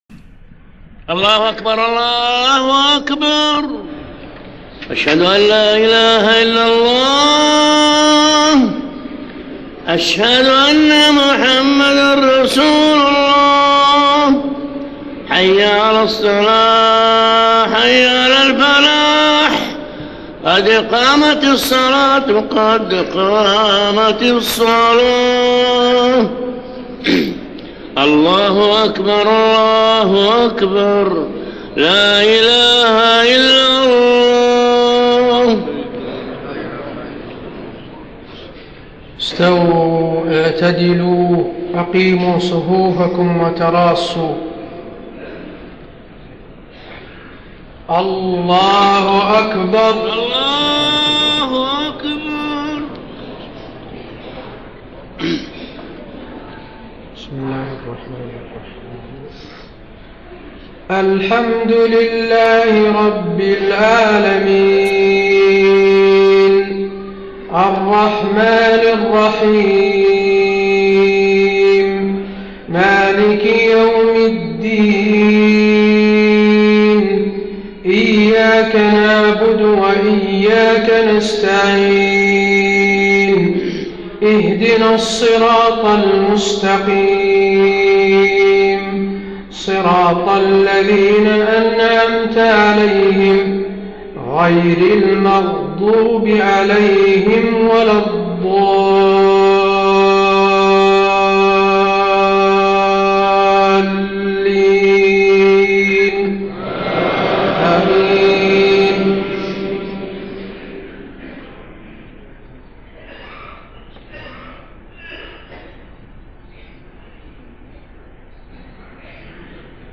صلاة المغرب 13 محرم 1430هـ سورتي الانفطار13-19 والإخلاص > 1430 🕌 > الفروض - تلاوات الحرمين